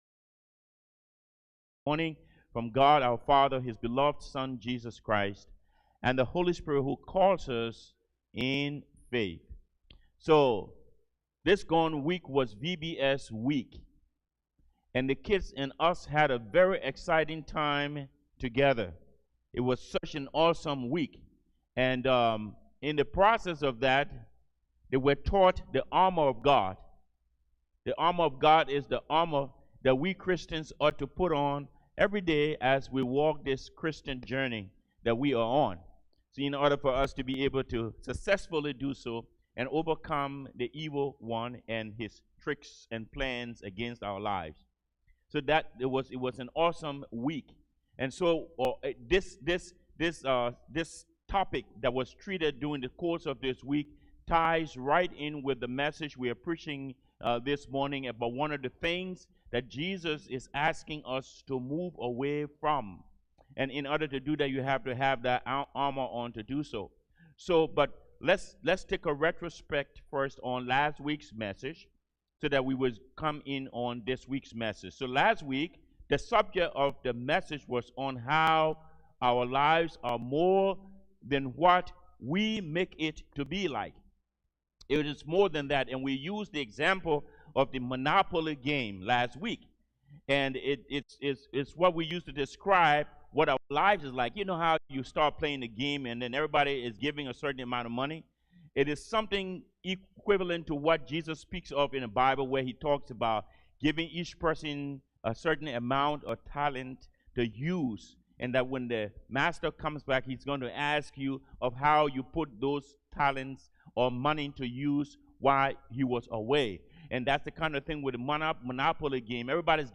Passage: Luke 12: 22-33 Service Type: Sermons « That’s So Nice